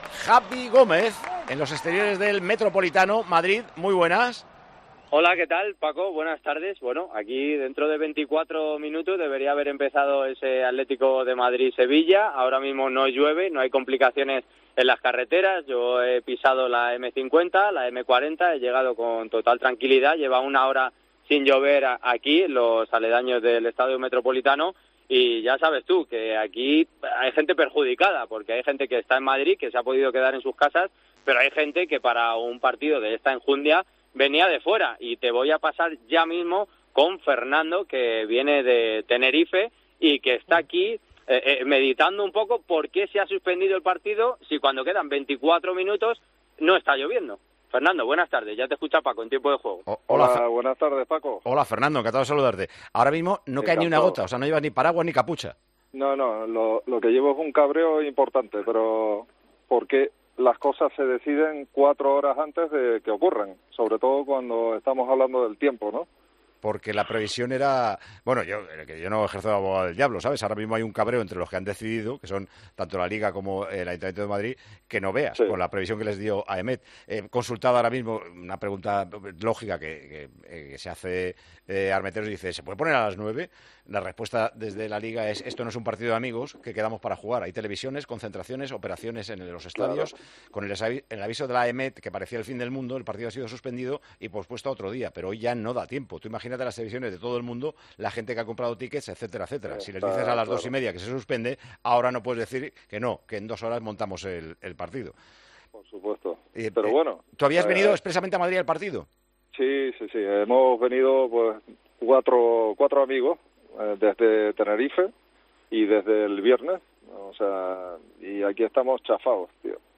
Escucha el fragmento de Tiempo de Juego en el que Paco González habla con este aficionado y en el que se queja de la decisión tomada.